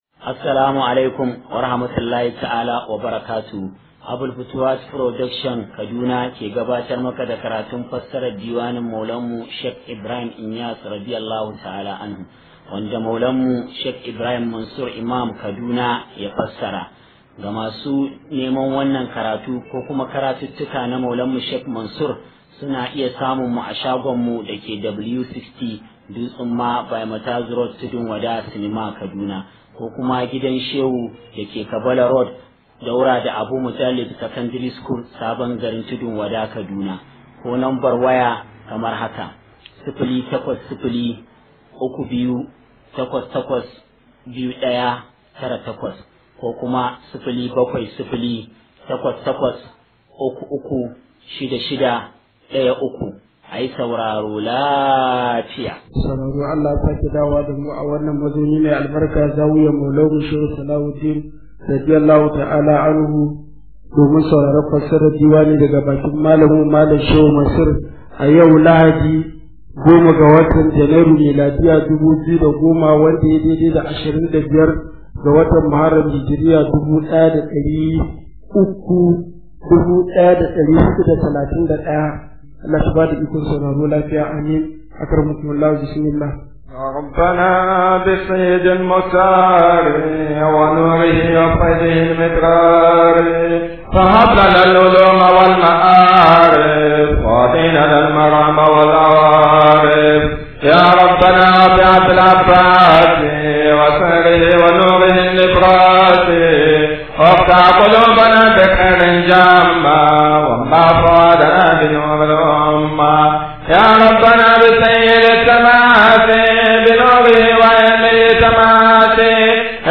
021 KARATUN DIWANI (Iksiyrussa'adati)